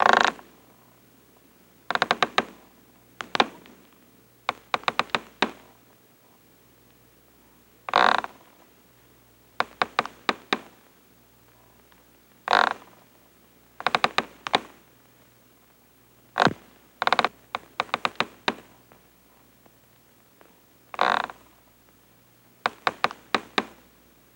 Rope Creaks, Moans, and Groans